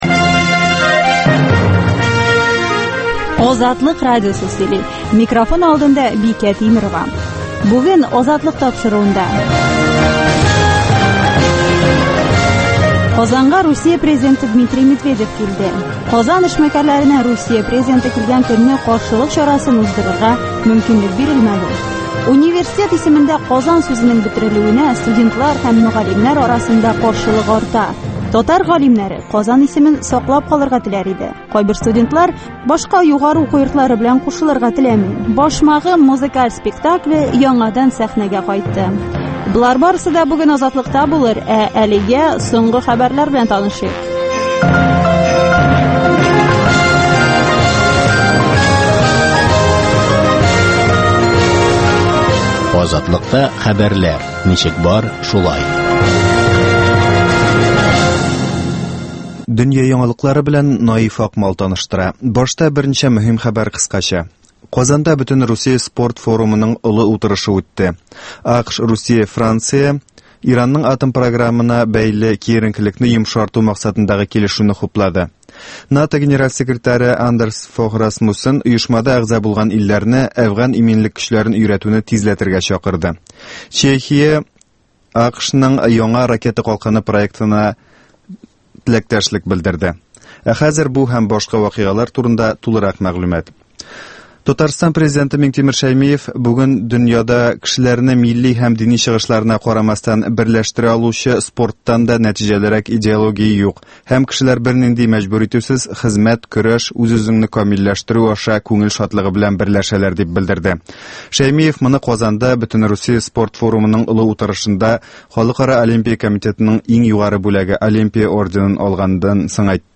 сәгать тулы хәбәр - дөнья хәлләре - татарстан яңалыклары - башкортстан яңалыклары - татар дөньясы тормышы